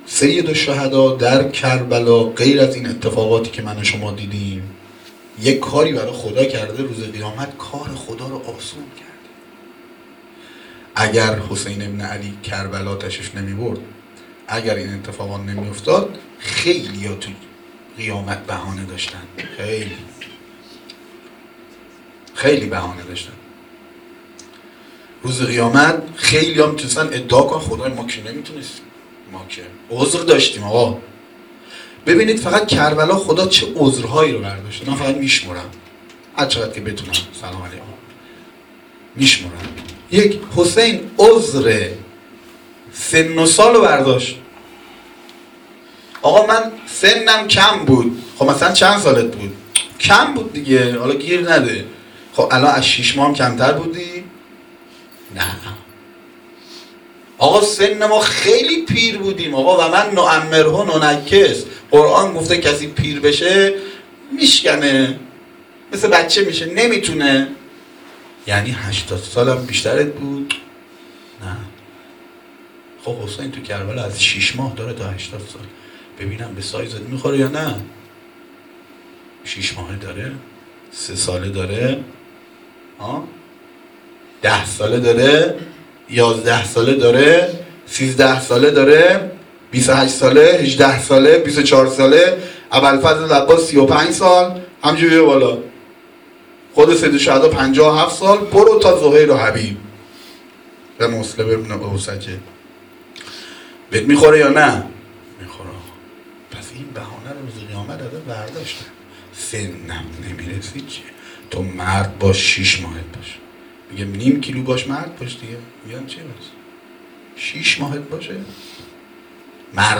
سخنرانی - بخش3.m4a